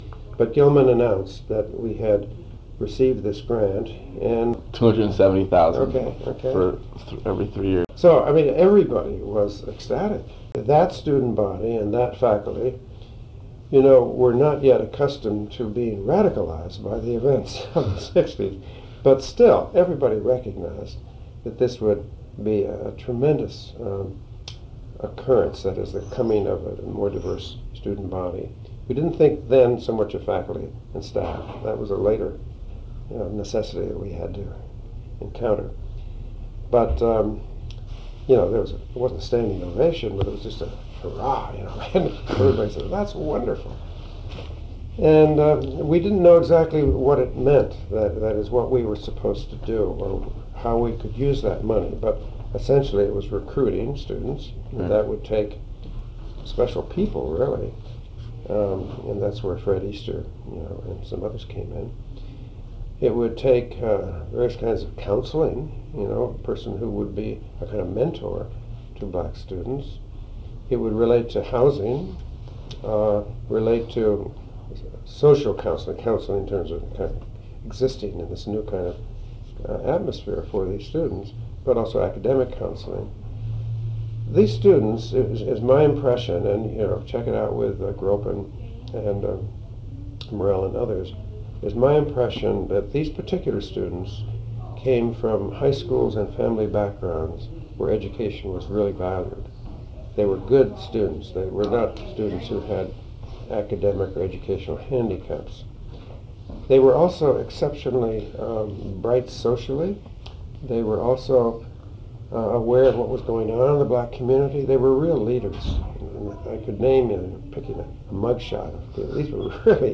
Interview of Emeritus Professor of Religion and Asian Studies
Original Format Audio Cassette